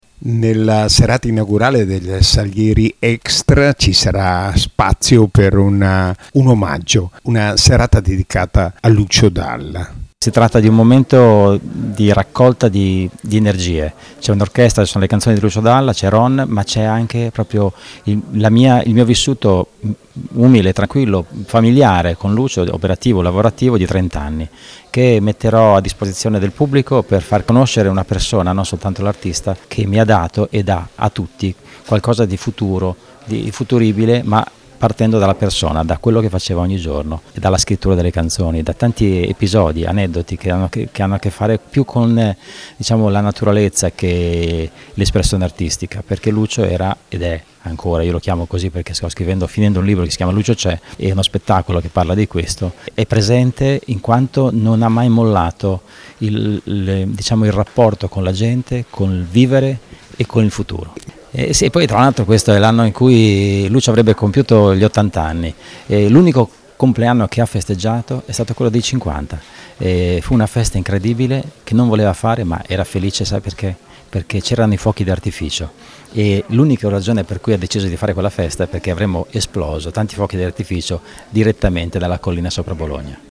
Ecco le dichiarazioni raccolte nella giornata di presentazione